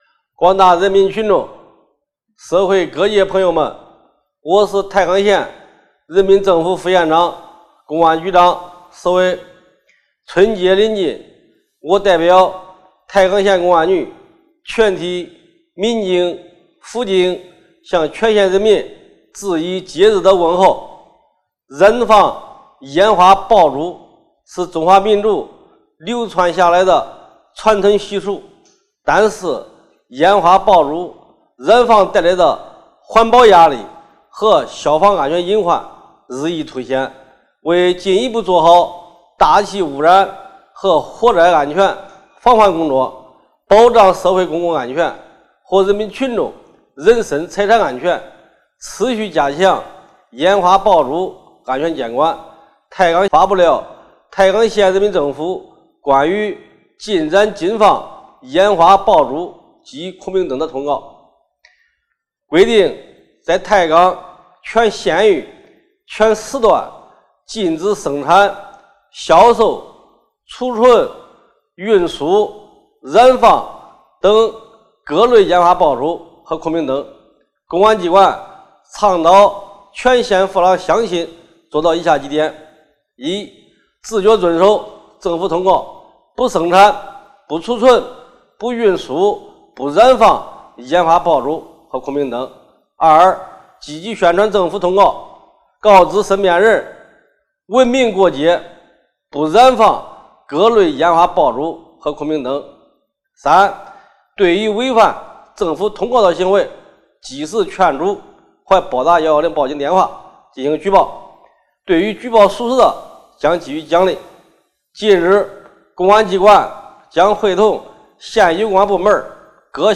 太和县副县长关于春节燃放烟花爆竹的讲话